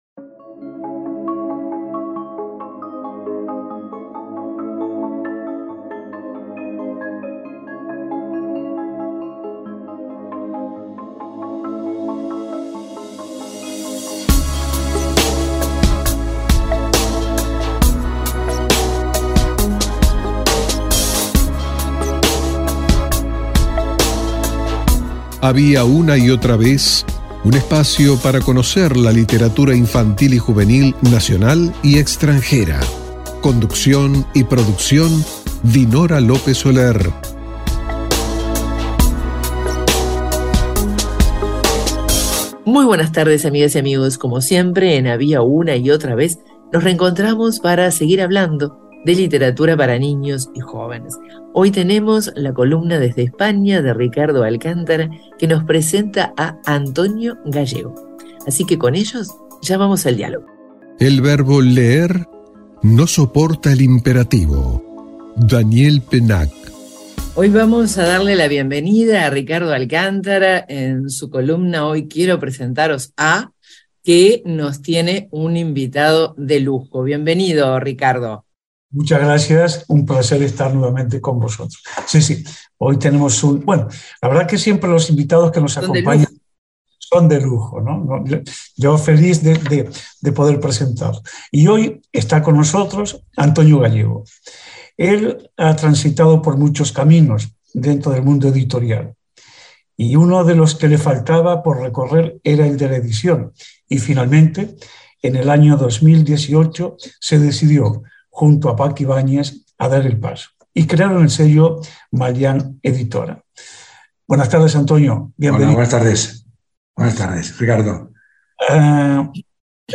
Entrevista al ilustrador